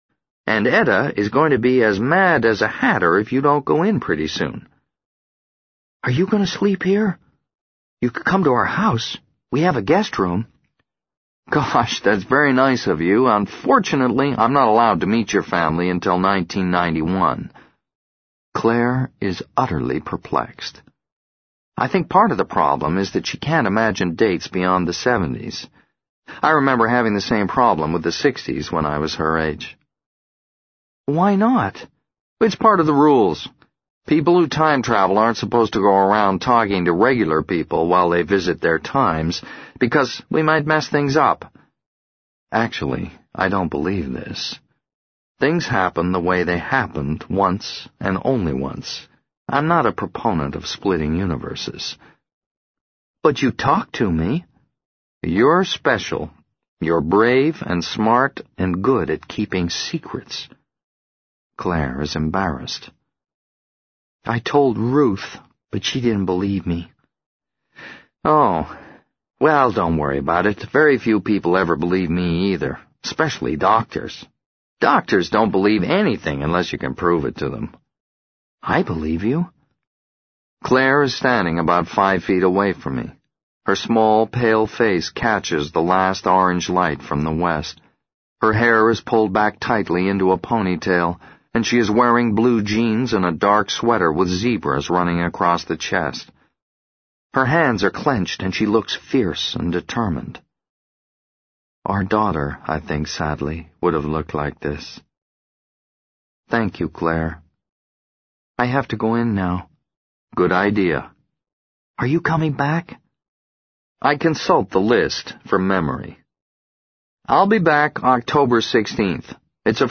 在线英语听力室【时间旅行者的妻子】40的听力文件下载,时间旅行者的妻子—双语有声读物—英语听力—听力教程—在线英语听力室